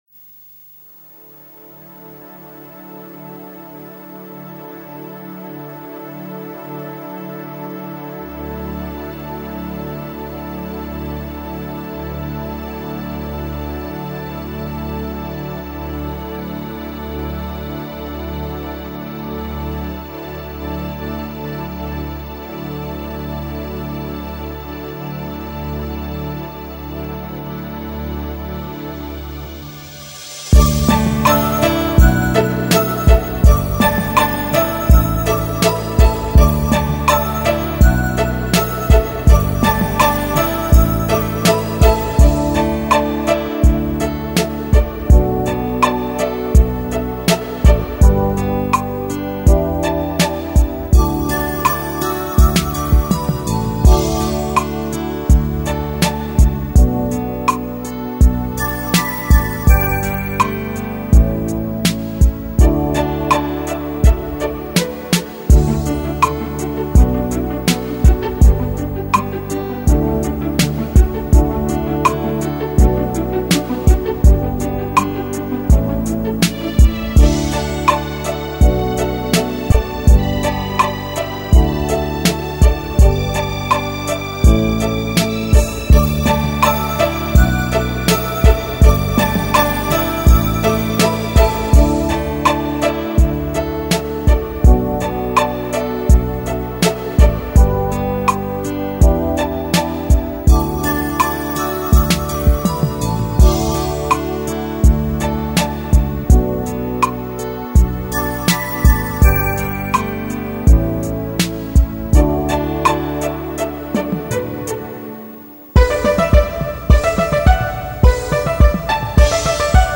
无 调式 : D 曲类